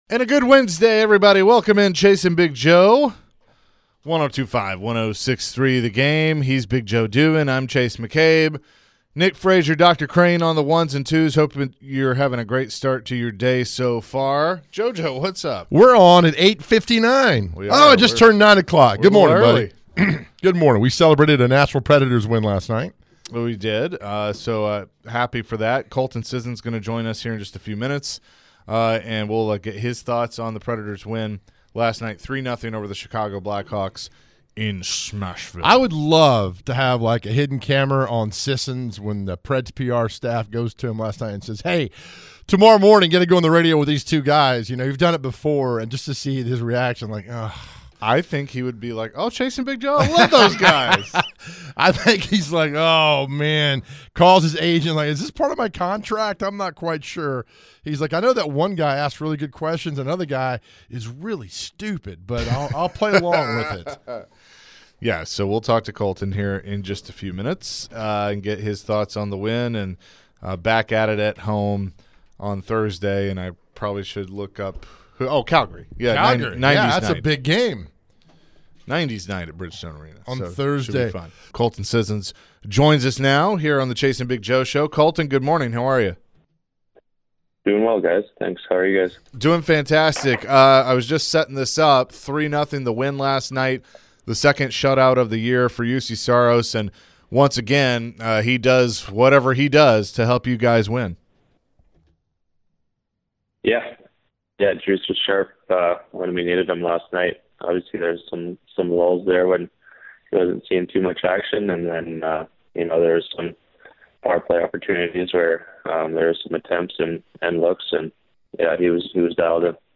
The guys chatted with Colton SIssions about the Preds big win over the Blackhawks last night. Sissions was asked about his play on the ice and the current chemistry within the team.